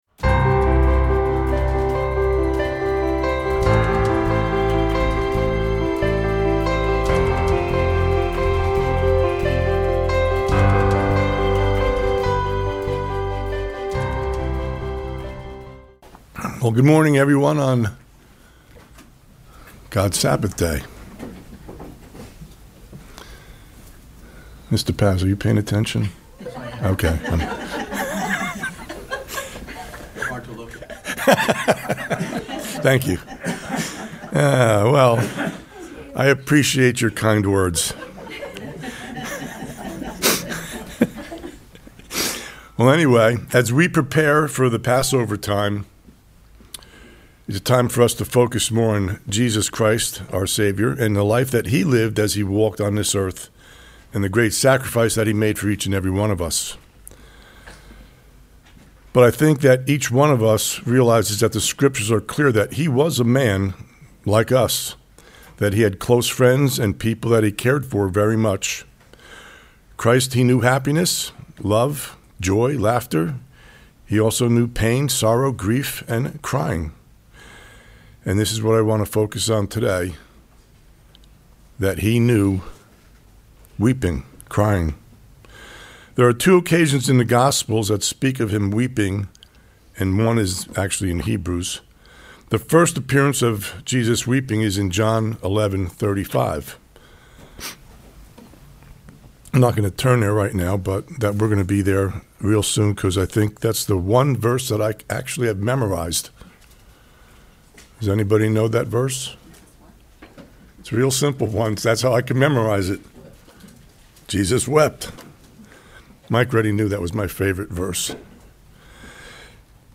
Sermons
Given in Charlotte, NC Hickory, NC Columbia, SC